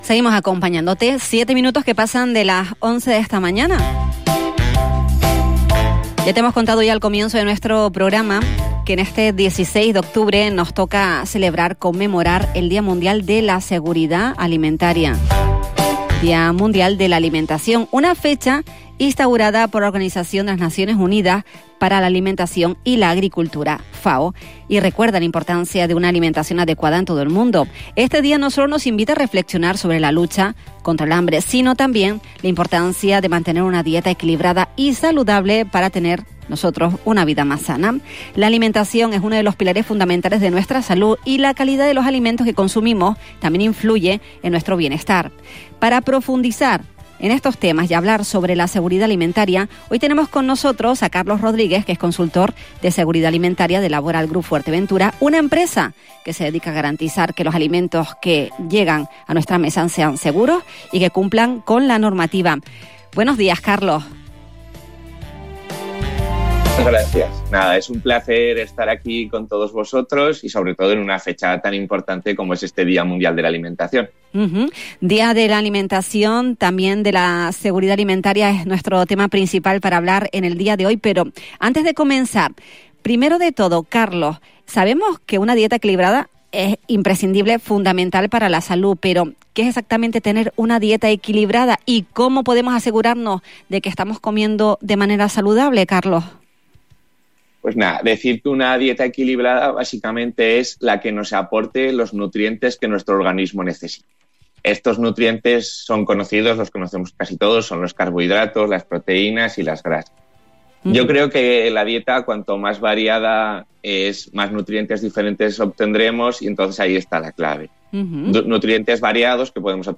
participó en el programa La Mañana Xtra de Radio Insular